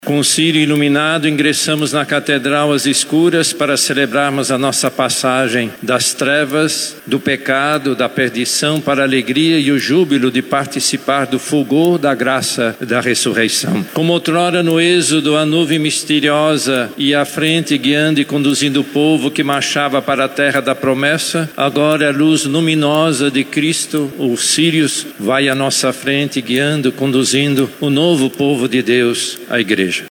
A vitória de Cristo sobre a morte, representa a luz sobre as trevas, a força inabalável, na qual o cristão deve se firmar para uma vida nova, disse o Cardeal em sua homilia.